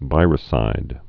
(vīrə-sīd)